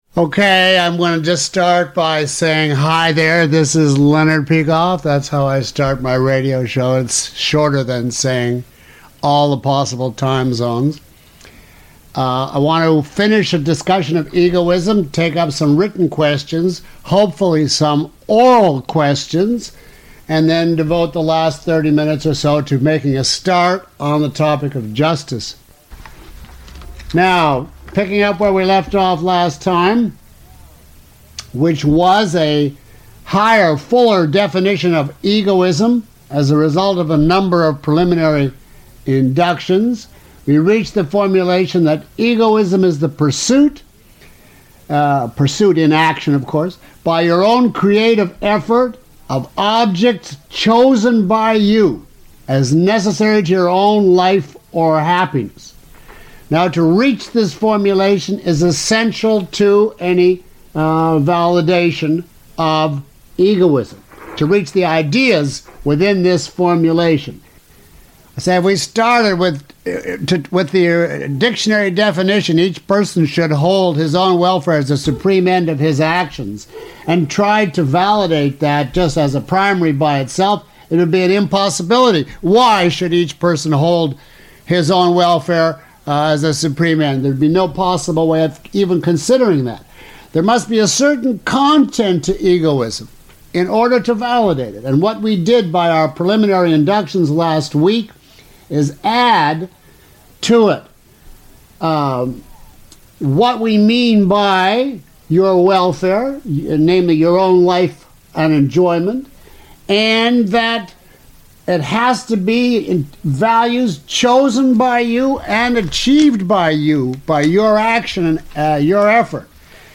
Lecture 04 - Objectivism Through Induction.mp3